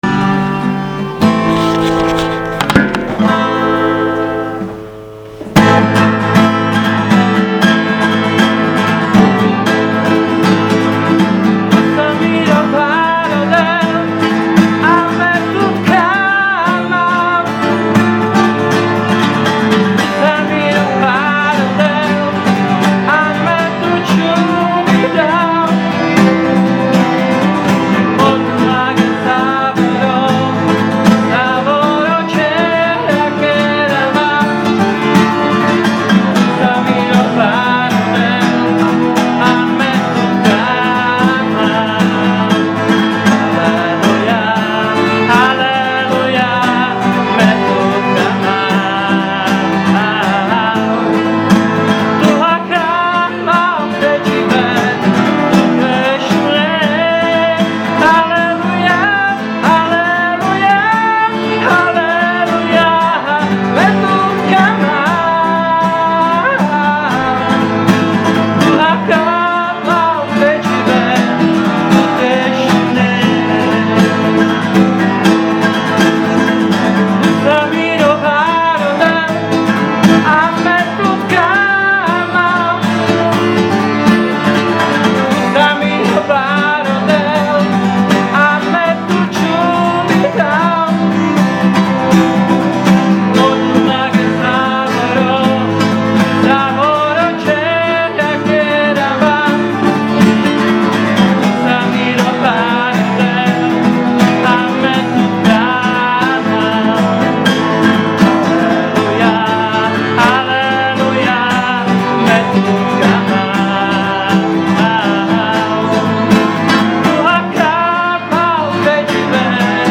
Audio: “New Creation” (with two Gypsy songs!)